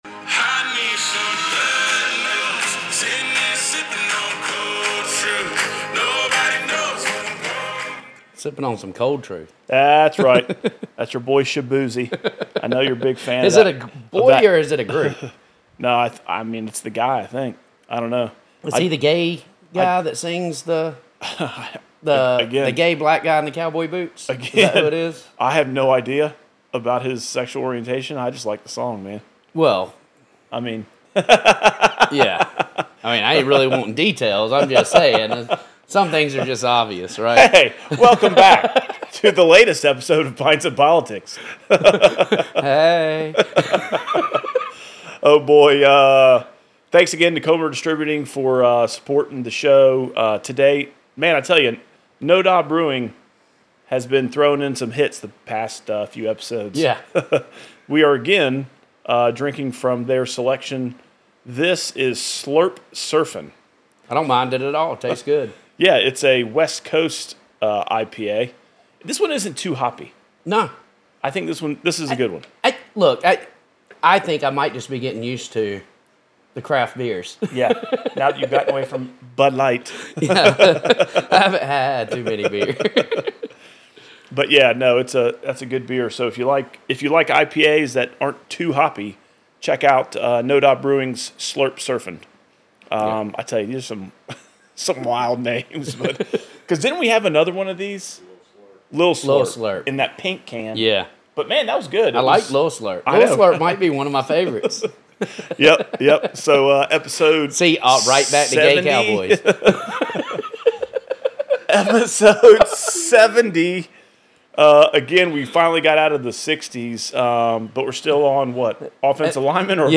Join us this week as SC House Reps Brandon Guffey & Heath Sessions enjoy another fine selection from NoDa Brewing, SLURP SURFIN and opine on the past few weeks. In this episode we hear about Guffey's speaking engagements and Heath participates in a healthcare panel while simultaneously becoming the Ad Hoc champion of the state house.